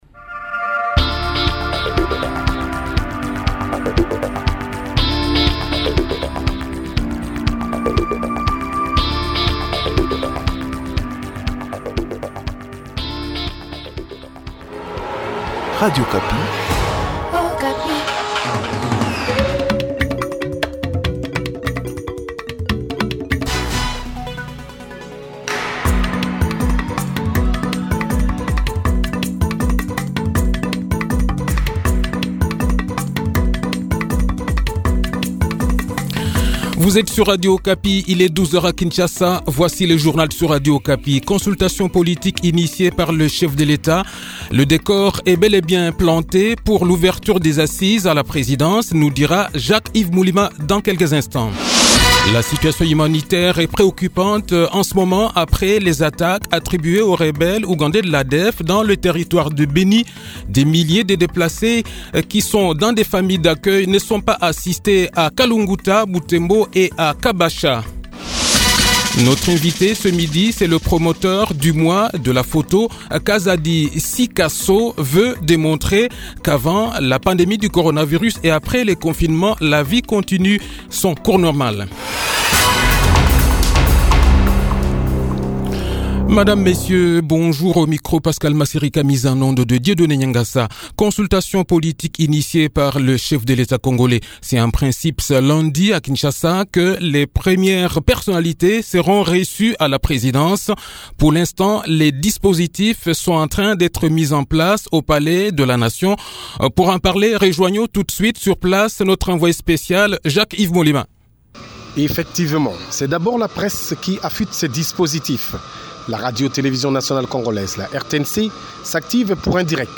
Le journal-Français-Midi